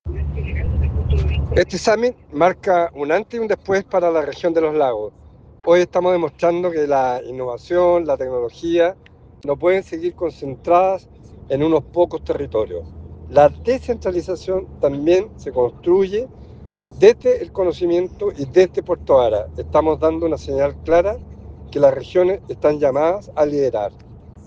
El gobernador regional de Los Lagos, Alejandro Santana, enfatizó que este evento representa un avance en la descentralización: “Hoy estamos demostrando que la innovación y la tecnología no pueden seguir concentradas en unos pocos territorios.
Cuna-1-Alejandro-Santana-gobernador-Los-Lagos.ogg